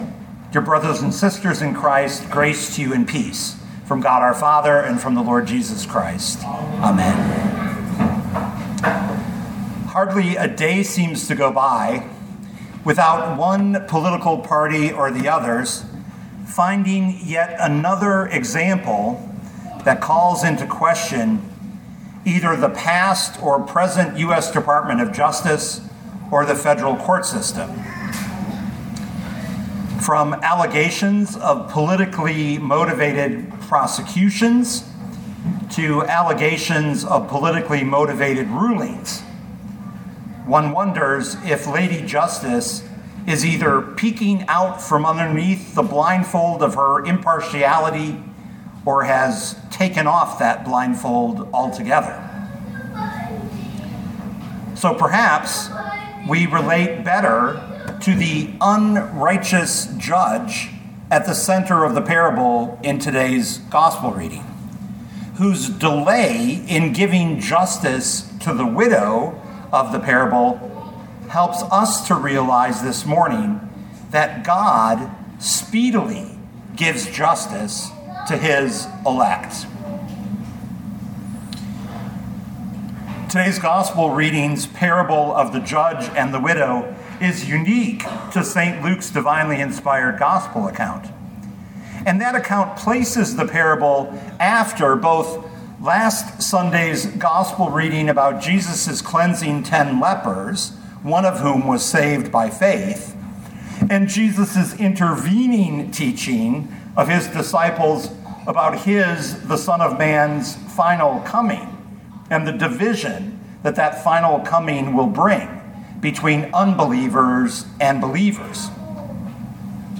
2025 Luke 18:1-8 Listen to the sermon with the player below, or, download the audio.